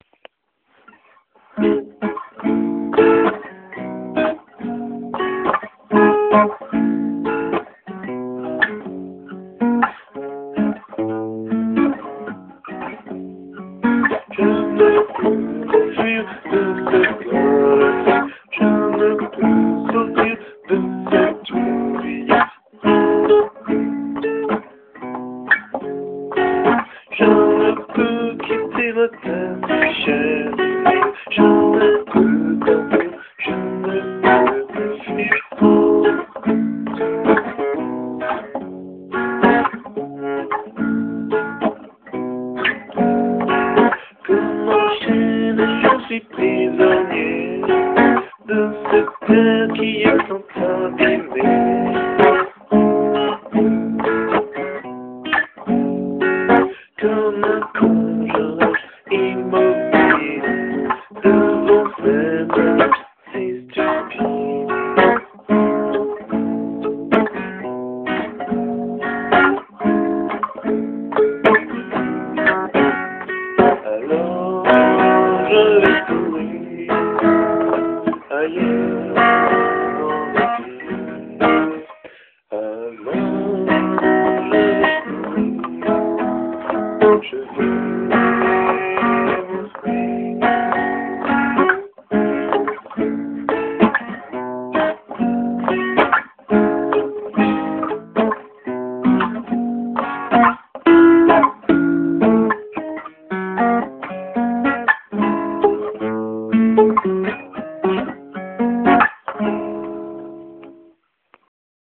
chant;guitare - RDBMS Expertise
Compo guitare